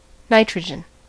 Ääntäminen
IPA : /ˈnaɪtɹədʒən/